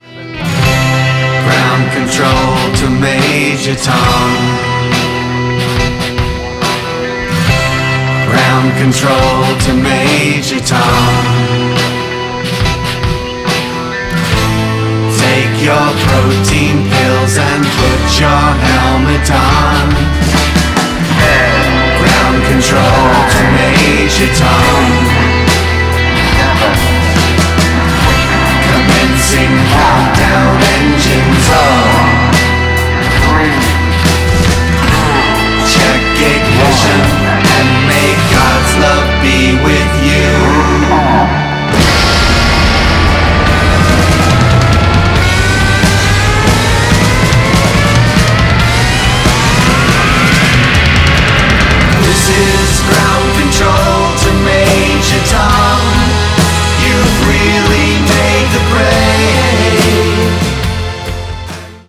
in epic fashion